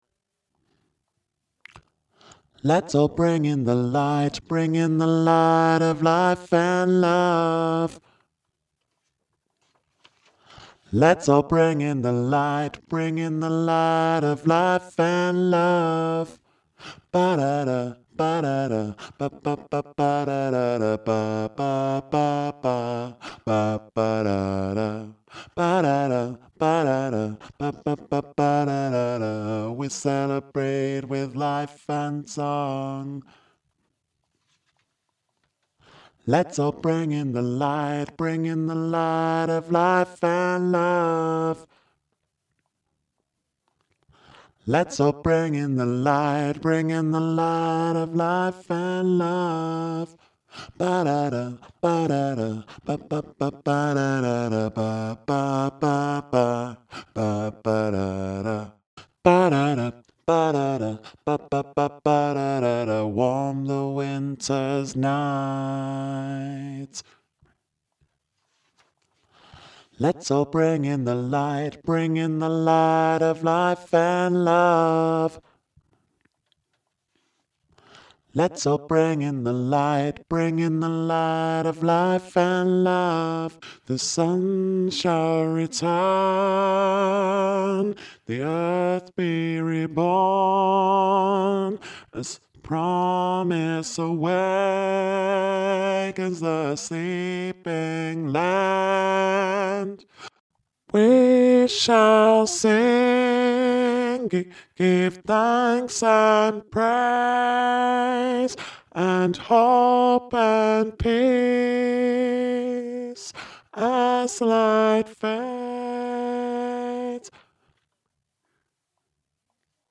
Bring-in-the-Light-Bass
Bring-in-the-Light-Bass.mp3